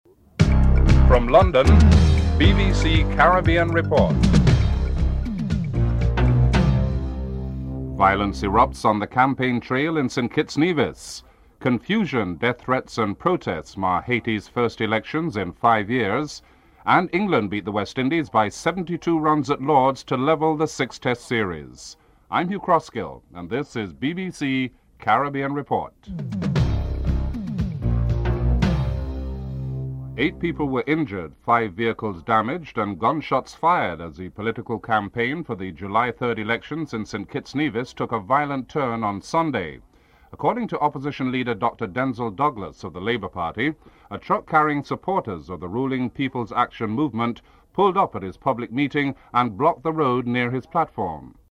This report examines the political campaign for the July 3rd elections in St. Kitts/Nevis which took a violent turn. Opposition Leader, Dr. Denzil Douglas and Prime Minister Kennedy Simmonds speak about the violence.